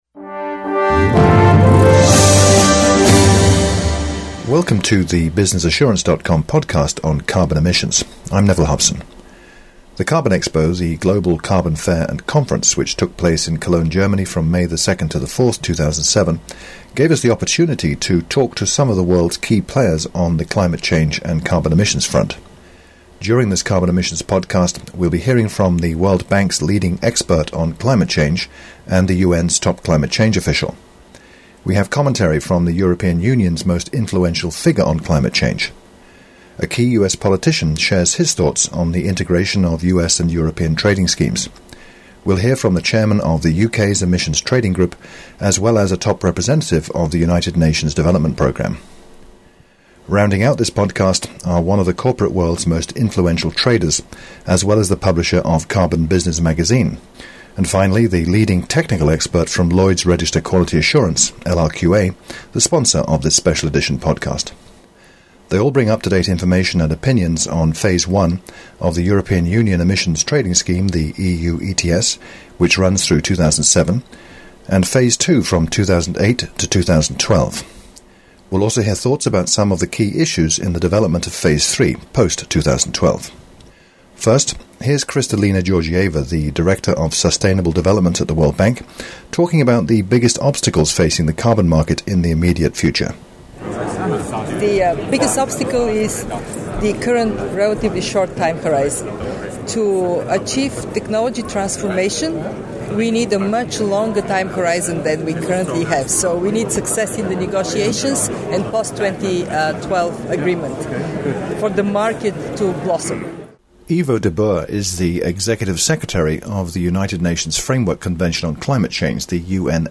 Carbon Emissions Podcast: Interview with Kristalina Georgieva and other Global Experts on Climate Change